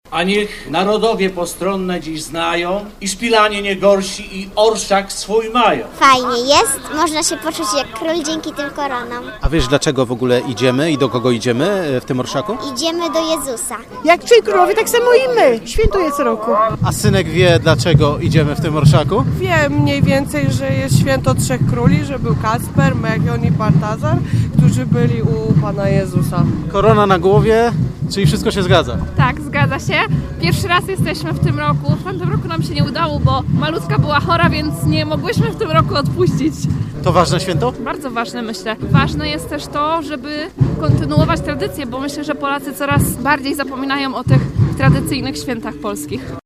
Później barwny korowód przy akompaniamencie bębniarzy ruszył w trasę wyznaczoną przez mędrców.